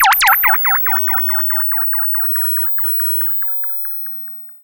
Theremin_FX_07.wav